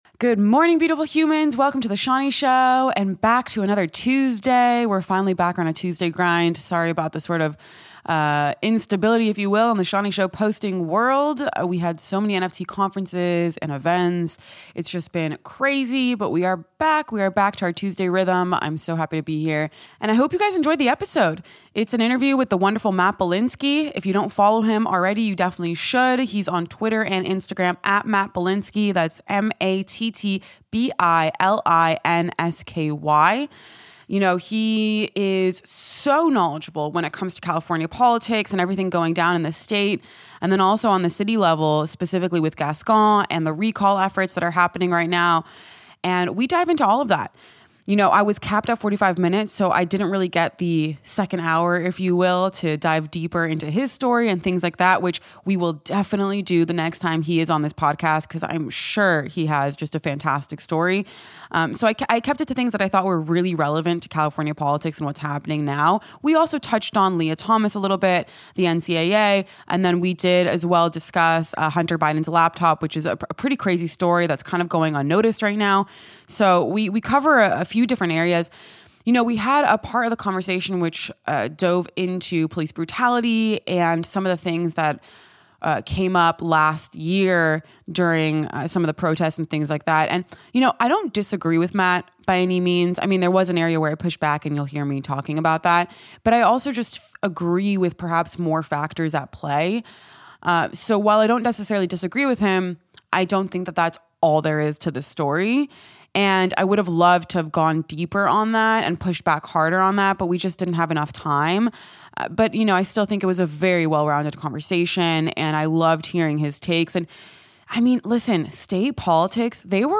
Tune in every Tuesday for sharp takes and interviews.